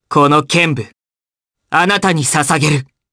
Fluss-Vox_Skill5_jp.wav